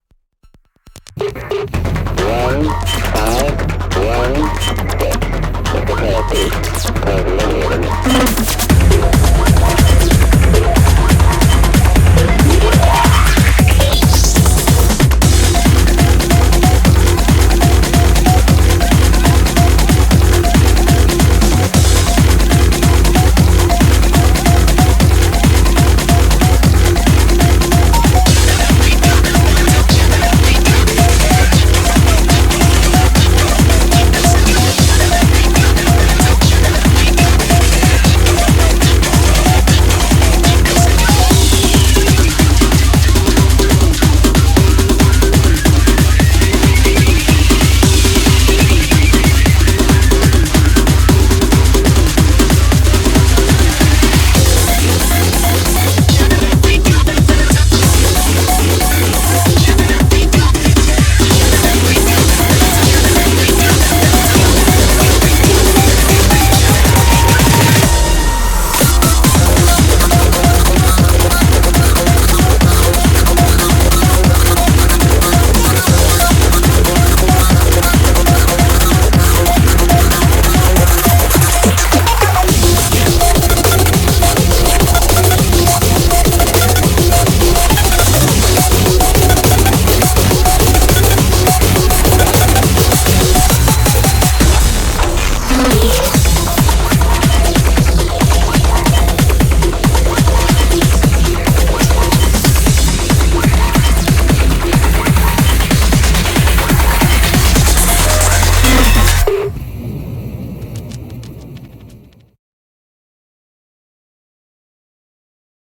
BPM138
Do you like songs with wack af time signatures?
Genre: ESOTERIC BEATS